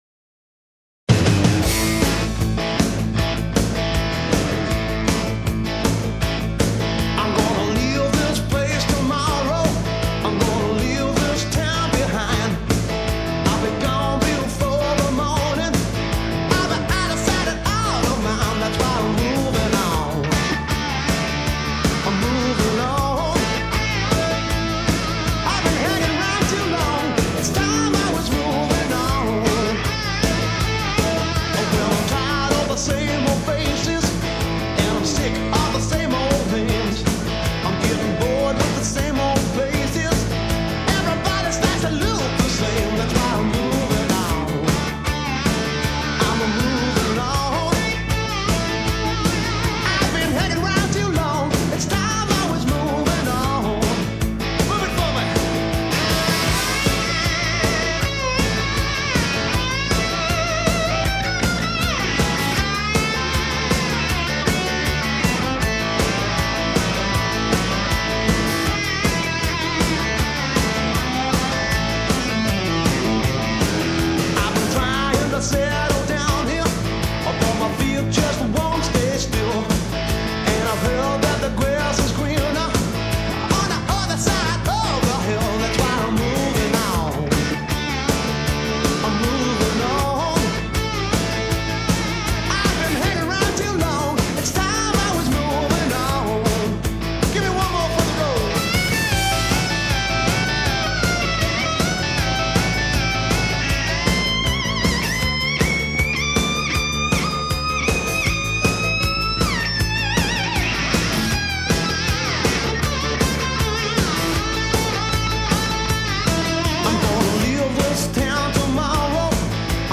Blues Rock